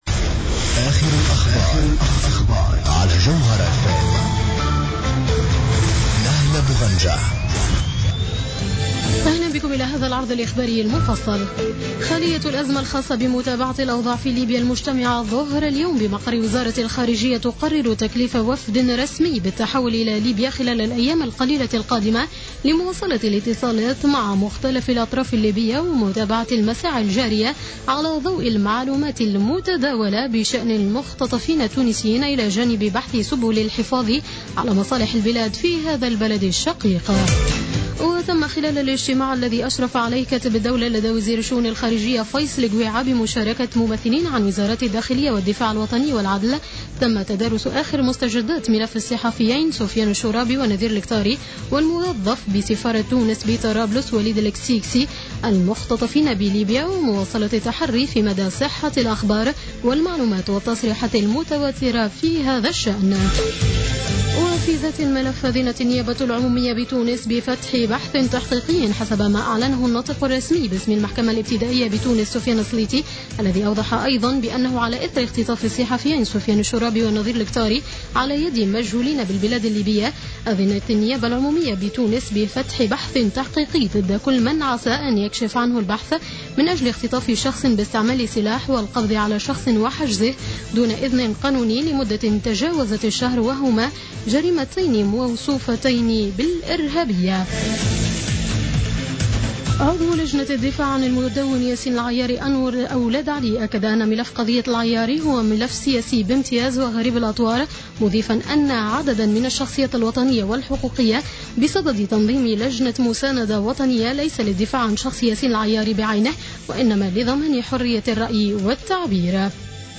نشرة اخبار السابعة مساء ليوم الإثنين 12-01-15